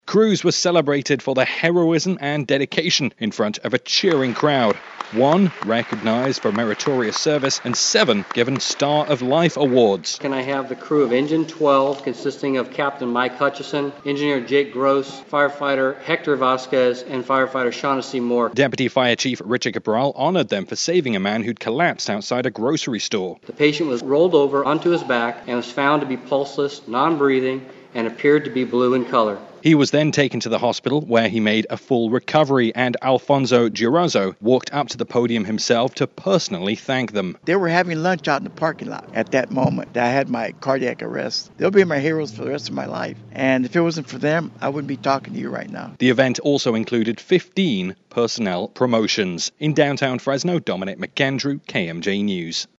FRESNO (KMJ) – Numerous Fresno Firefighters were honored inside Fresno City Hall, recognizing their dedication and heroism in the line of duty.